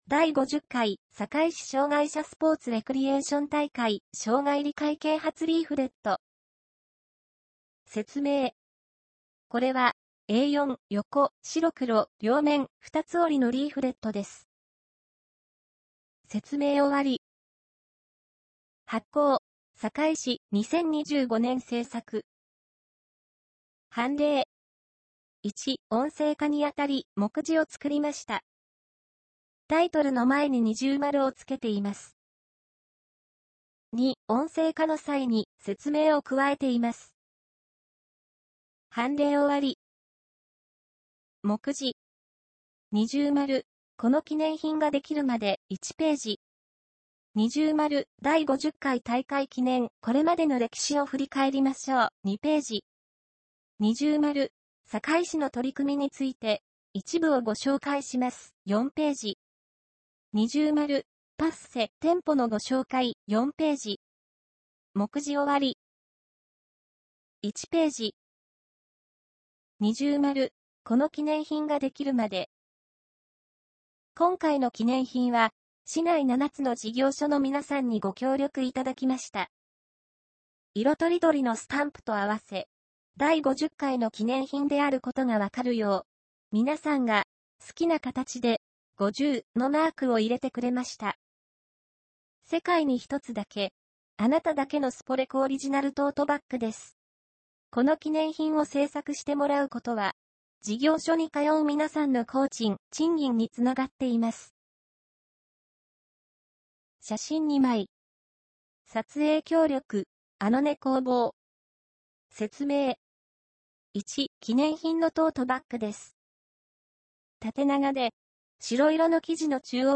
第50回堺市障害者スポーツ・レクリエーション大会障害理解啓発リーフレット（合成音声版）はこちら(音声:2,622KB)